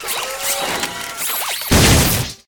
robot punch.ogg